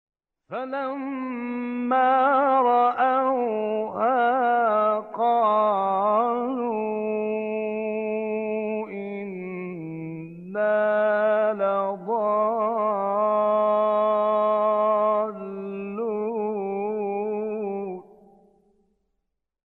گروه شبکه اجتماعی: مقاطعی صوتی از راغب مصطفی غلوش که در مقام حسینی اجرا شده است، می‌شنوید.
این مقاطع که در مقام حسینی اجرا شده‌اند، در زیر ارائه می‌شوند.